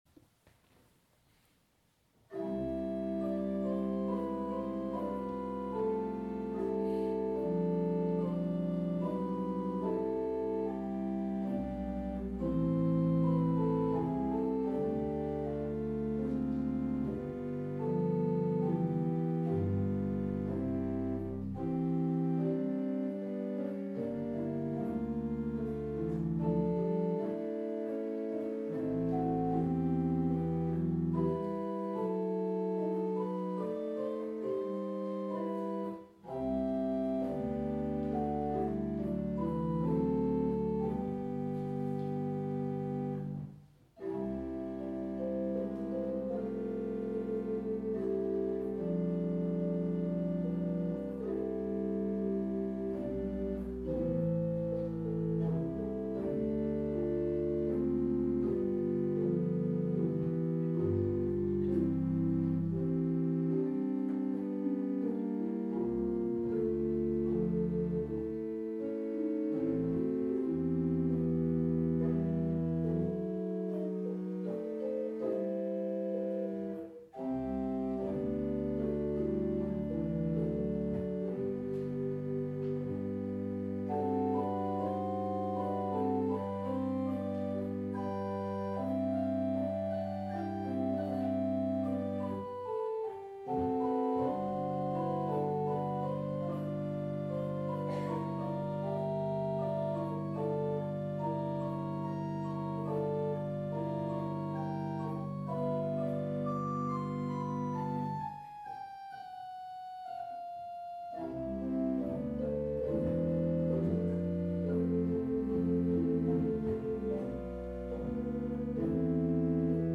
Sorry, I did not record the first reading.